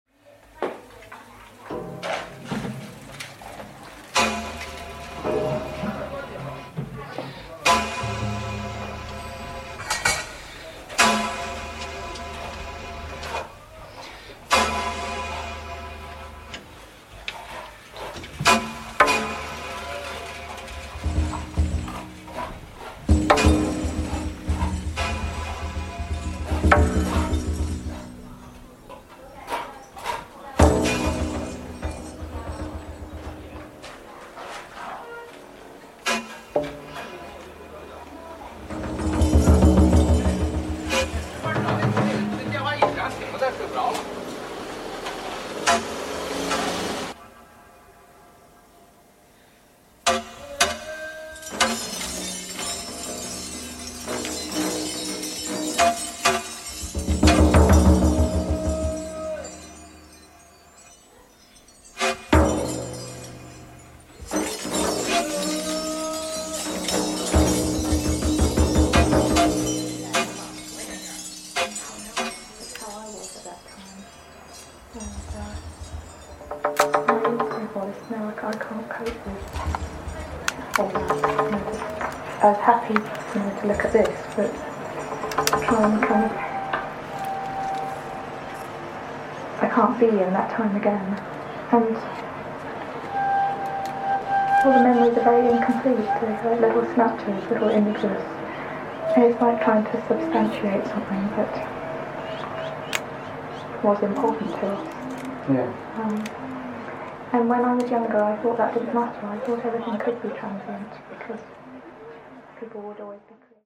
Field recordings forget, just as memories forget.